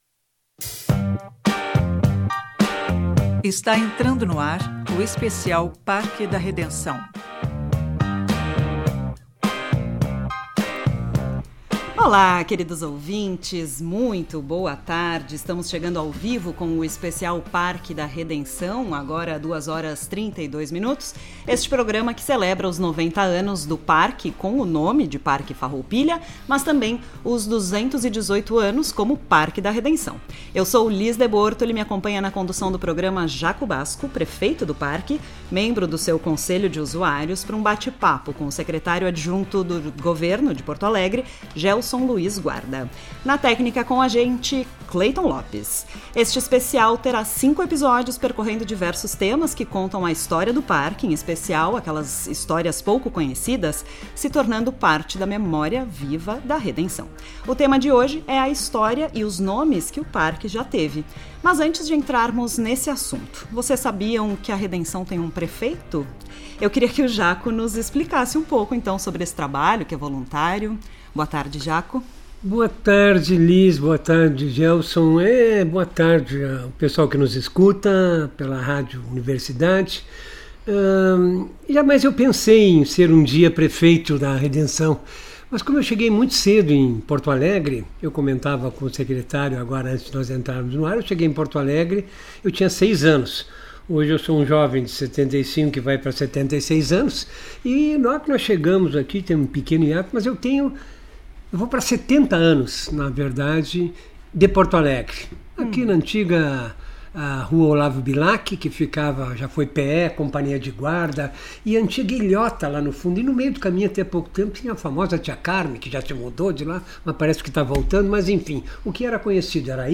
ESPECIAL REDENÇÃO AO-VIVO 21-11-25.mp3